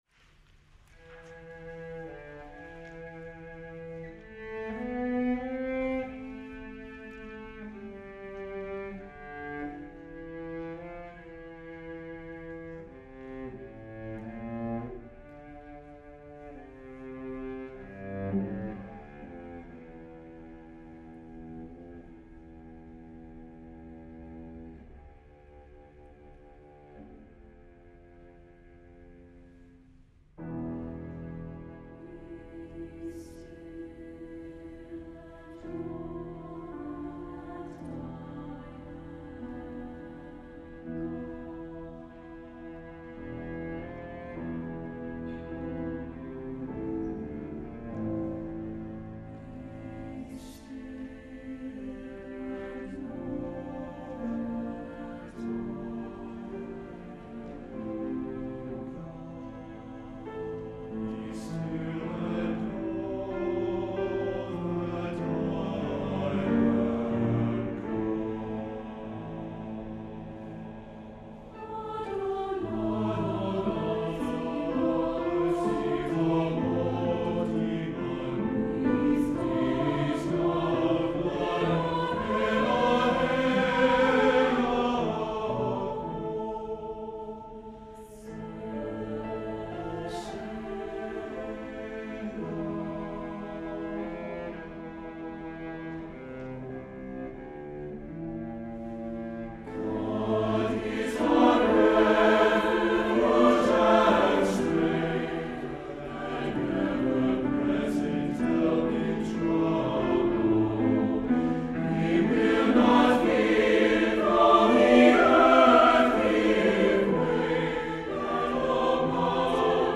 A lyrical and rhythmic setting of Psalm 42.
SATB, piano and cello